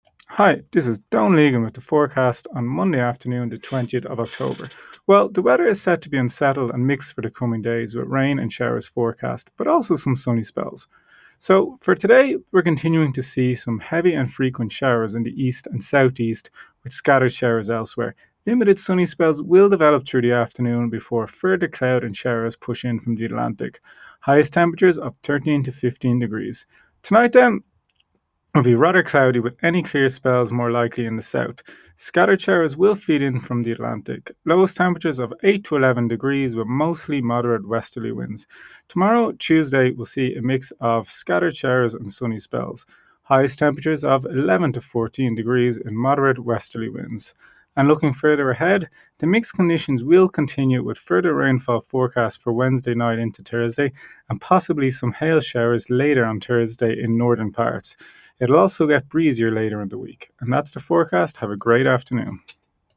Weather Forecast from Met Éireann / Ireland's Weather 2pm Monday 20 October 2025